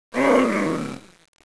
zombie_growl2.wav